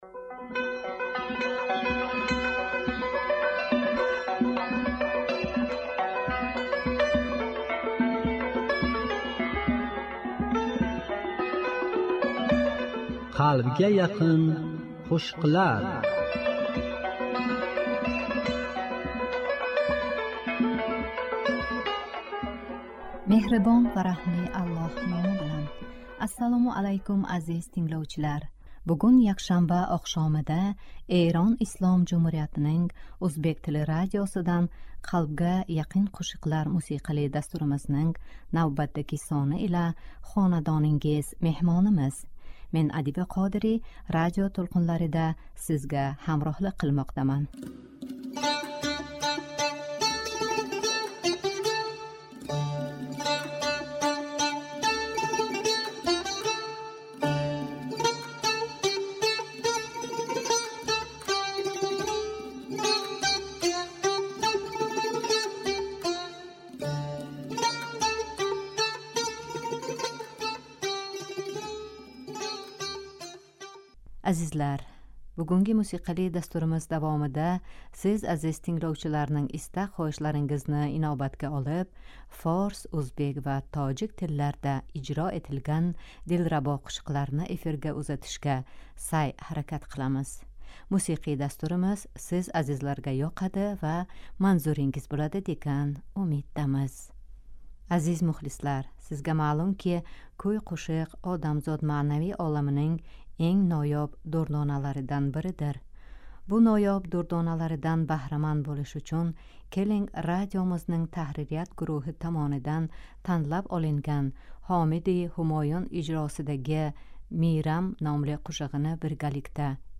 Бугунги якшанбе оқшомида Эрон Ислом Жумҳуриятининг ўзбек тили радиосидан "Қалбга яқин қўшиқлар"мусиқали дастуримизнинг навбатдаги сони ила хонадонингиз меҳмонимиз.